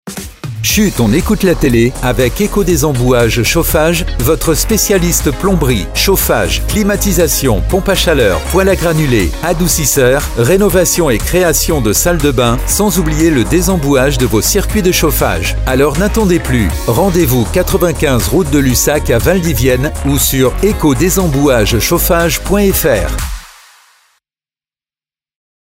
et voici le spot de notre annonceur